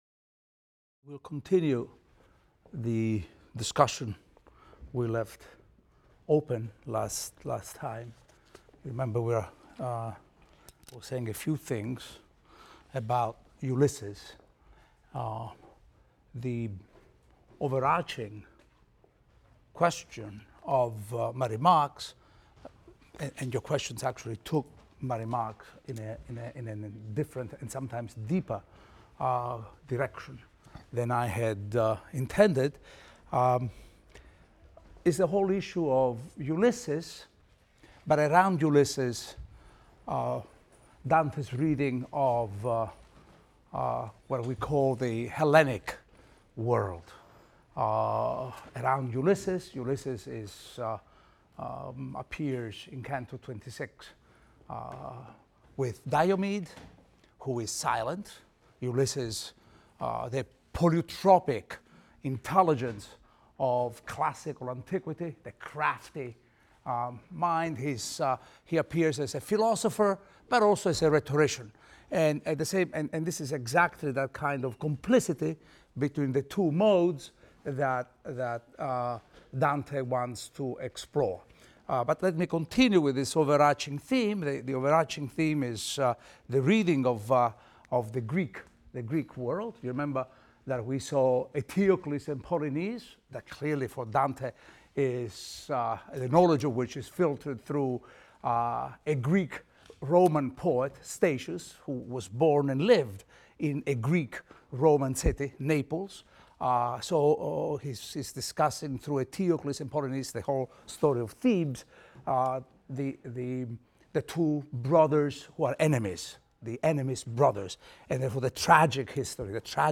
ITAL 310 - Lecture 8 - Inferno XXVI, XXVII, XXVIII | Open Yale Courses